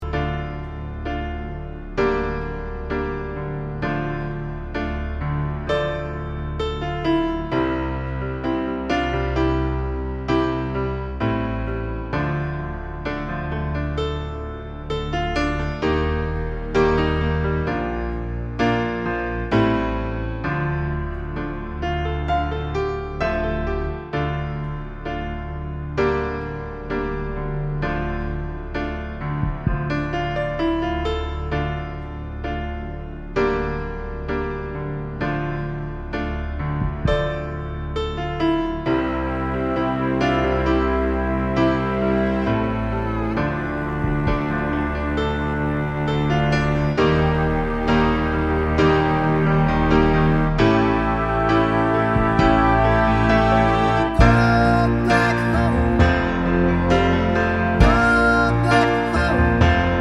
Long Version Pop (1970s) 4:55 Buy £1.50